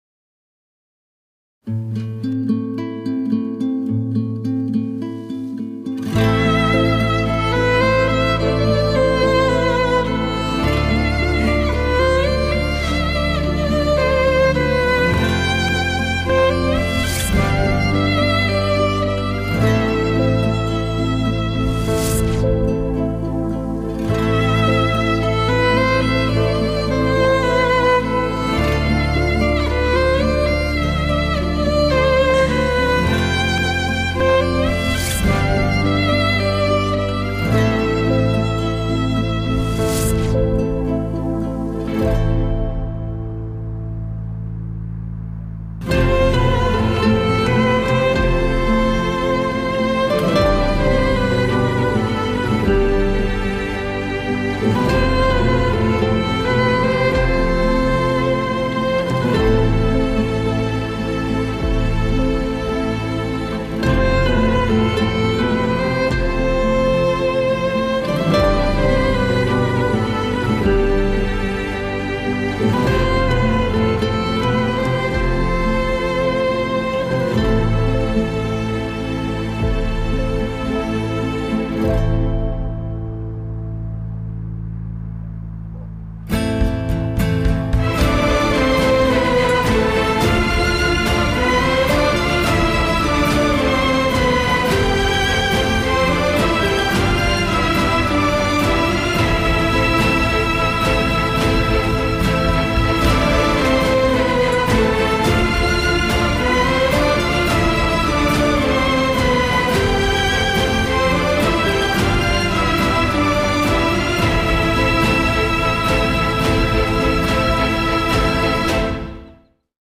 tema dizi müziği, duygusal huzurlu rahatlatıcı fon müzik.